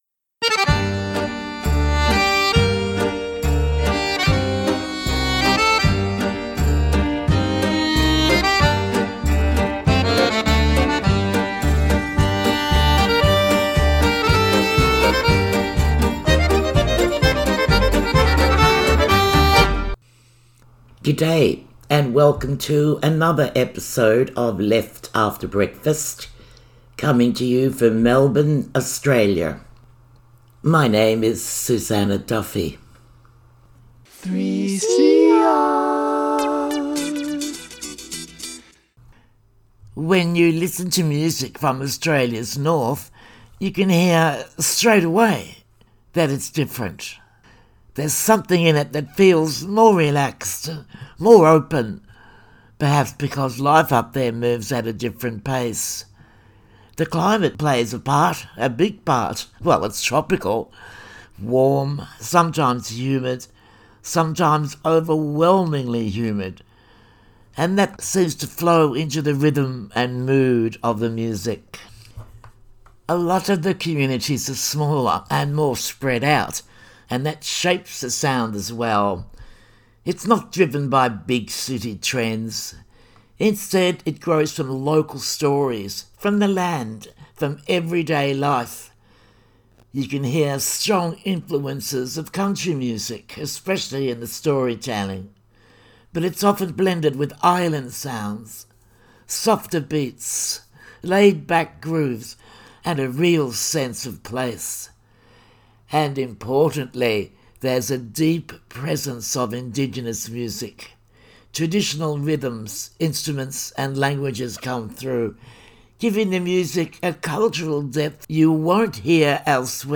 Chill to sounds shaped by the warm, coastal environments of the Top End